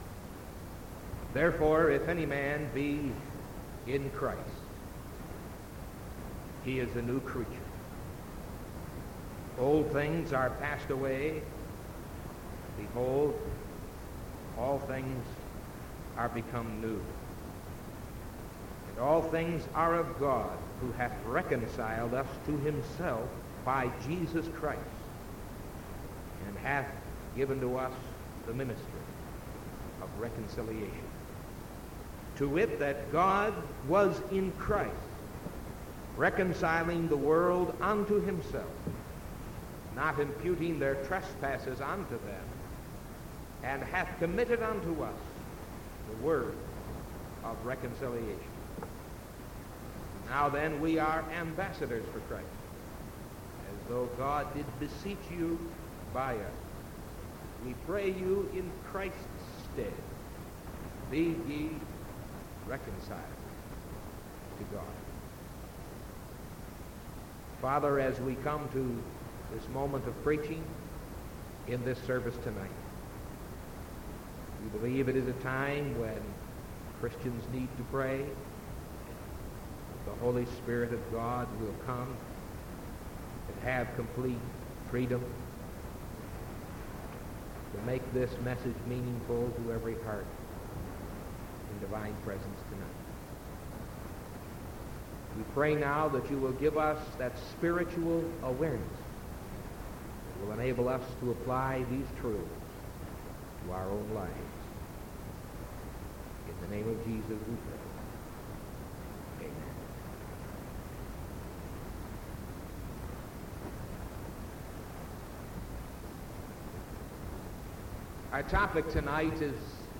Sermon January 5th 1975 PM